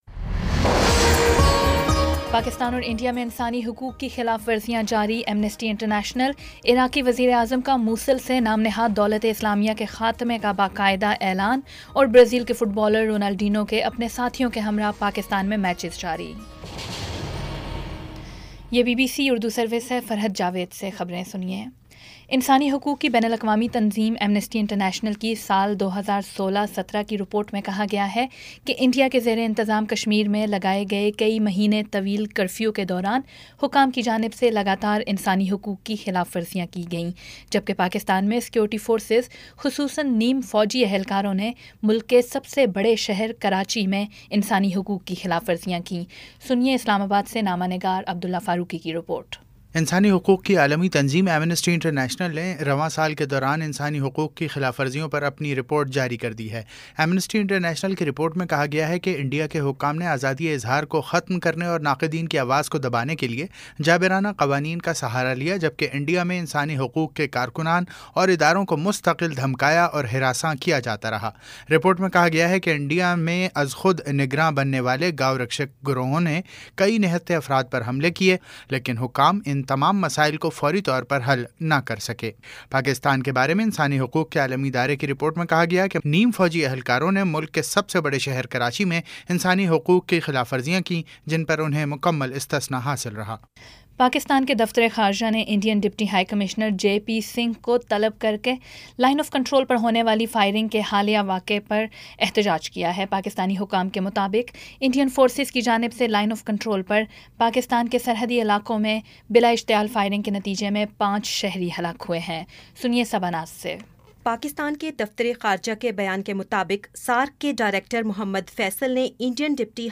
جولائی 09 : شام چھ بجے کا نیوز بُلیٹن